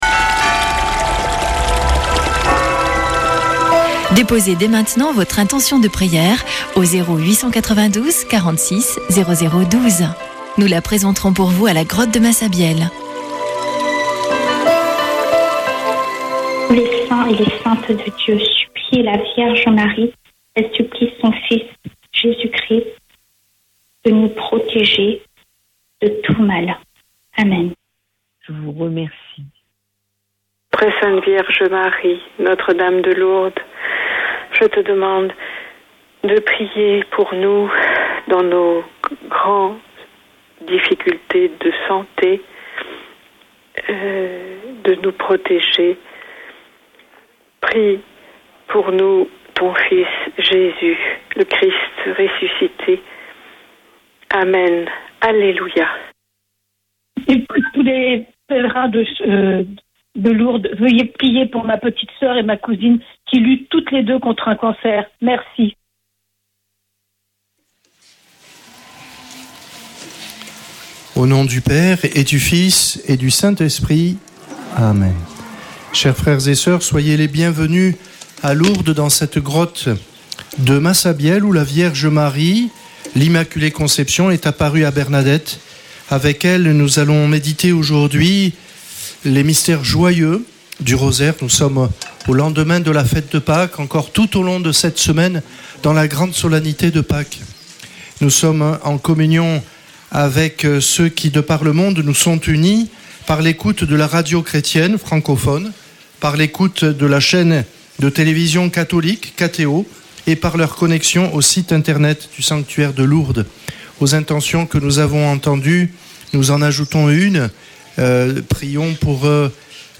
Chapelet de Lourdes du 06 avr.
Une émission présentée par Chapelains de Lourdes